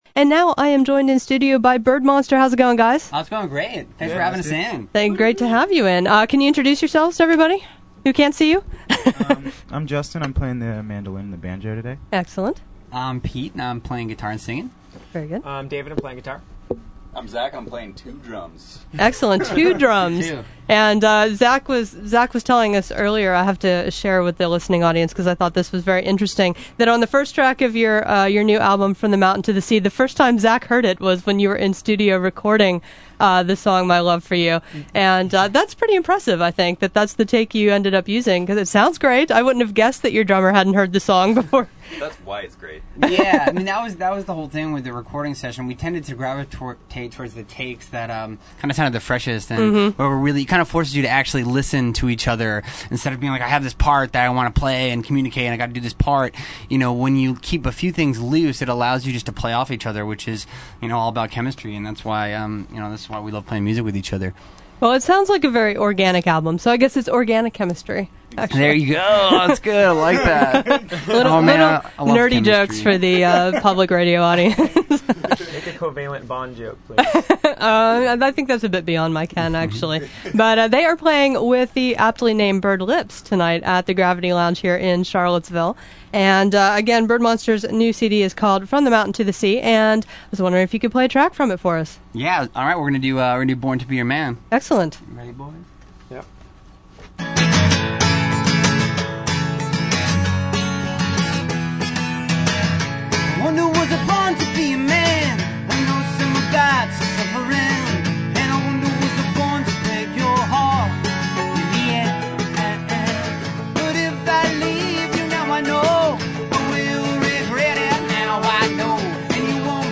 interview
They played a few songs